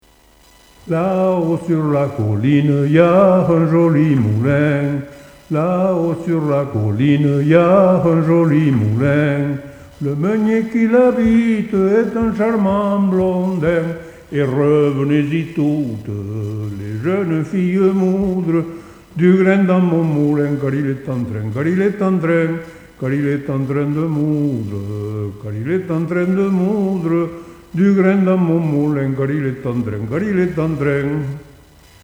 Origine : Tarn
cassette audio
original chanté (extrait)